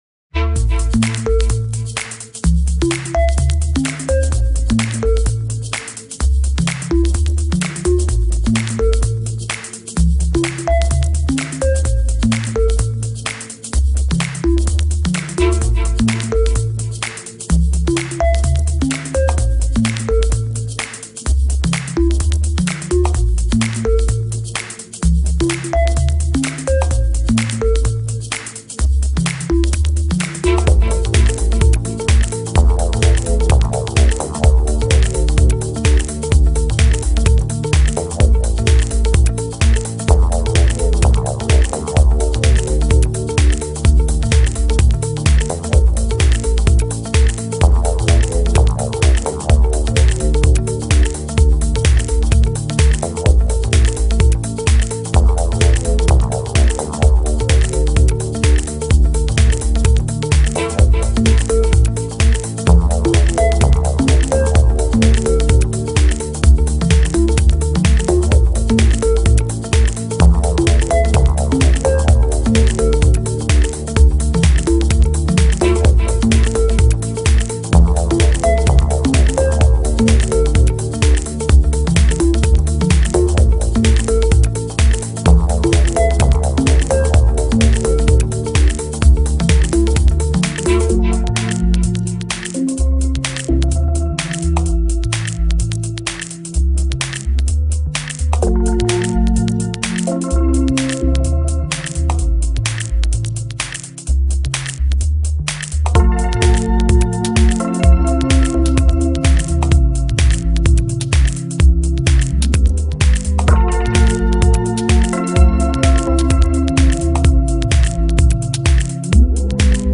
Chill Out